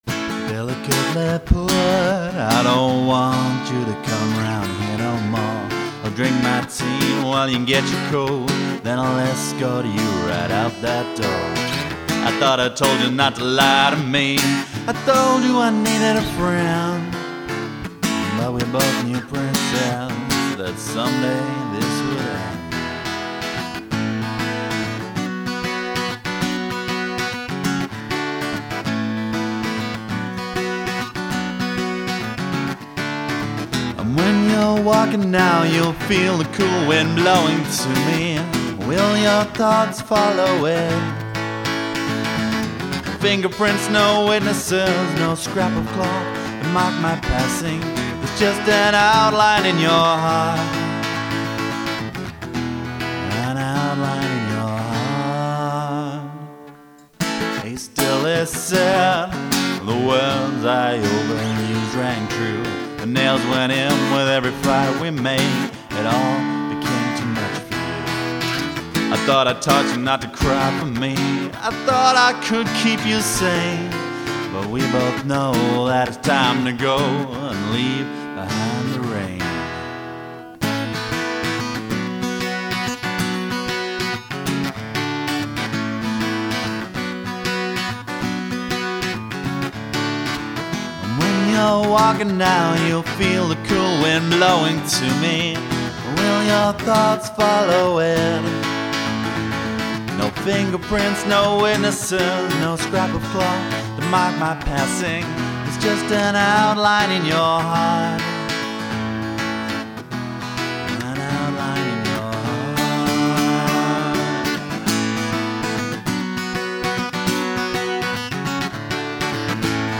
Songs - only rough demos, but they'll give you the idea:
A love song, of sorts